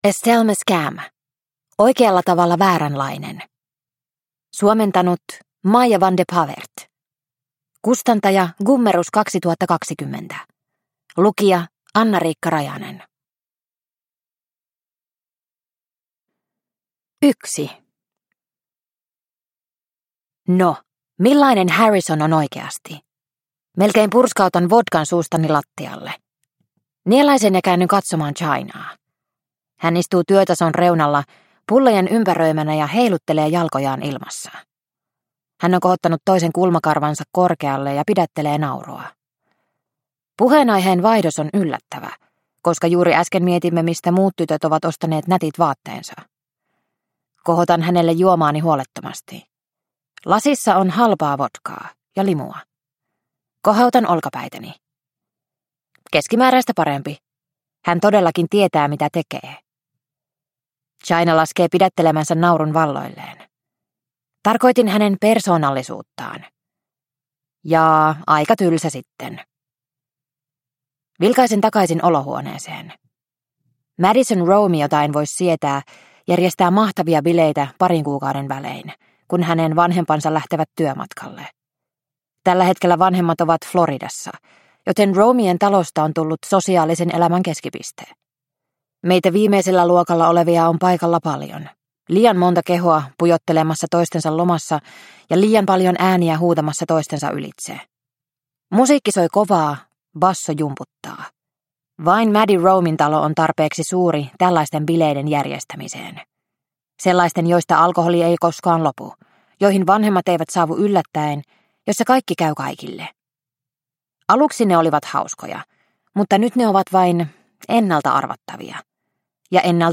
Oikealla tavalla vääränlainen – Ljudbok – Laddas ner